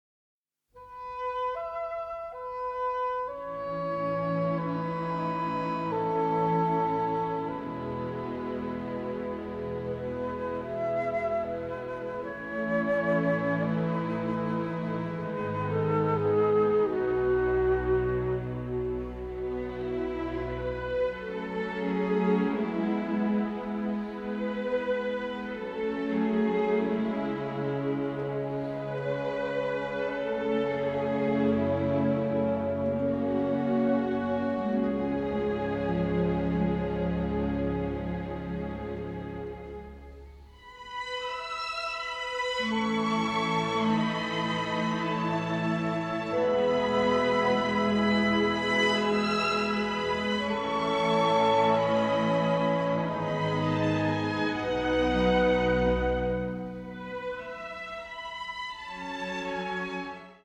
original motion picture soundtrack